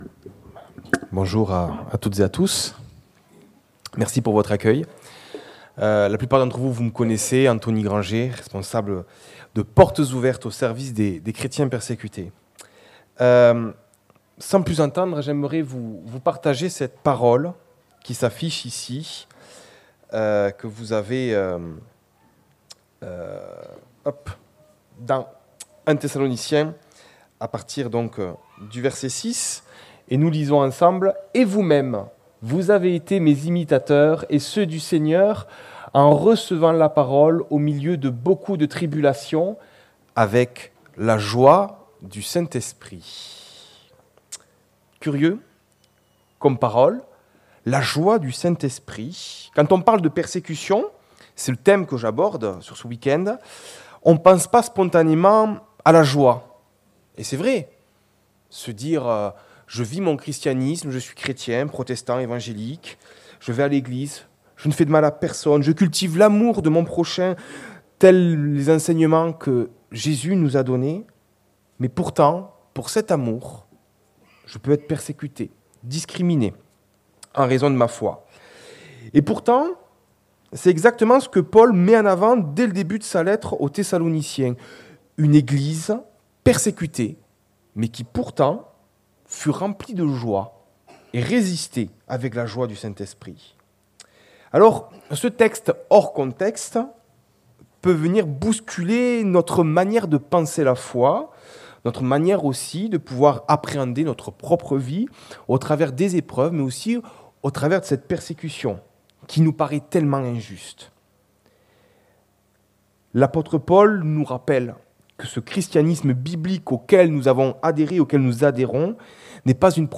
Persévérer avec joie dans la persécution - Prédication de l'Eglise Protestante Evangélique de Crest
1 Thessaloniciens Prédication textuelle